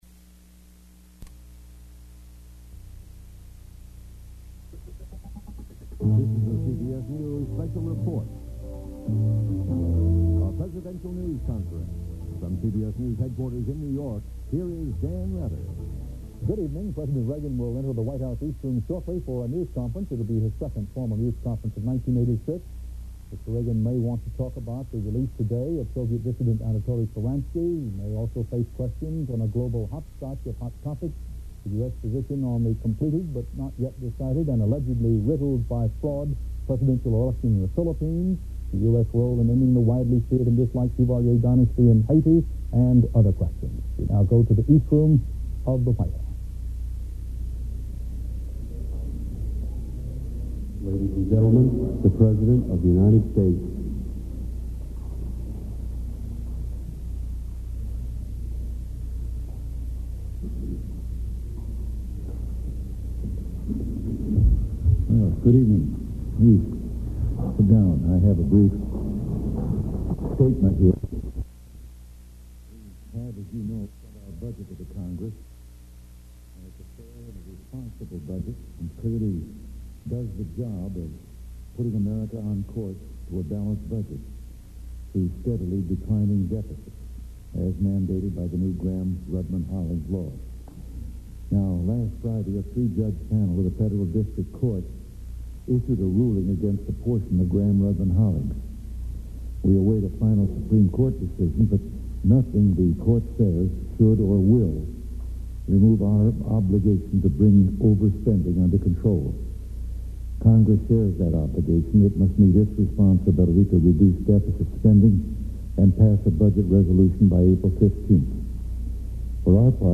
U.S. President Ronald Reagan's second press conference of 1986